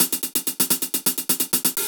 UHH_AcoustiHatA_128-05.wav